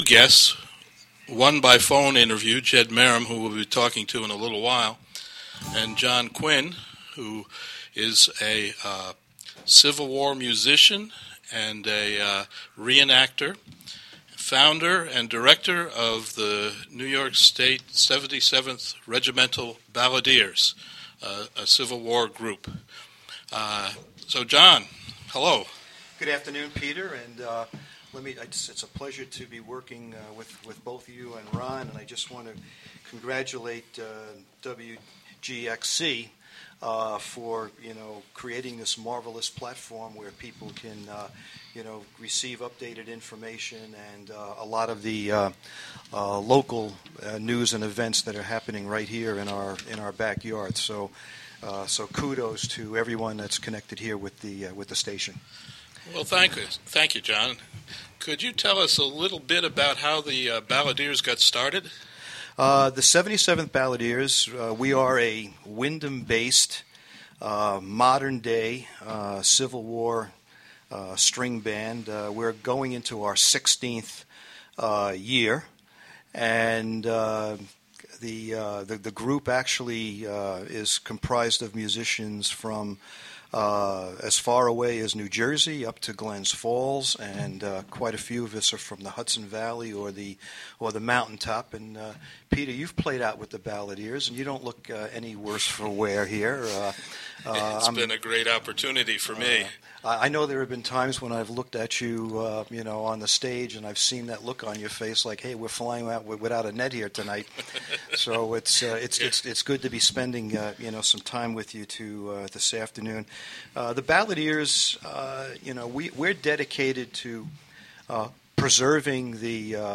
will be in the WGXC studio at the Catskill Community Center to talk about Civil War music and reenacting.